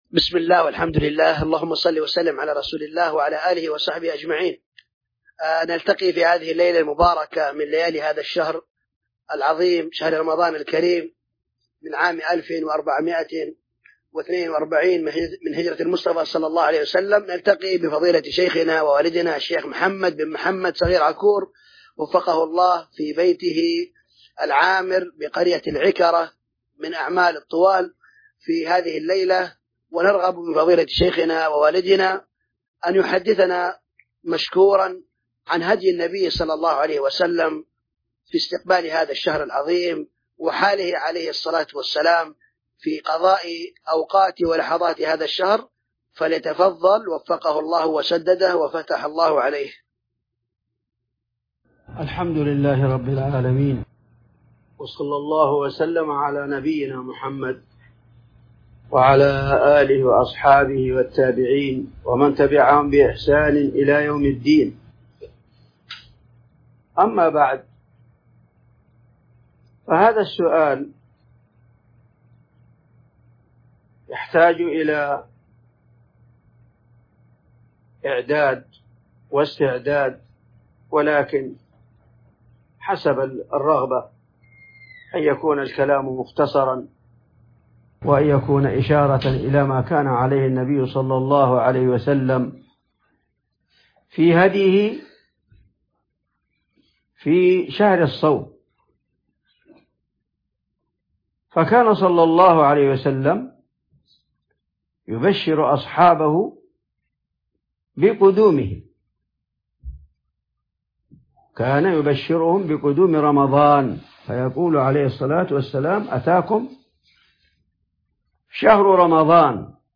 محاضرات وكلمات